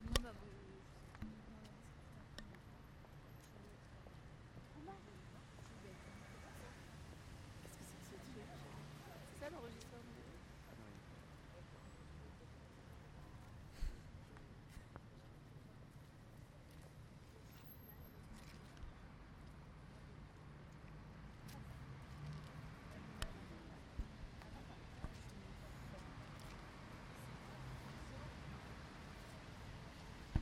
Limoges: Place d'Aine
Vent, voitures, passants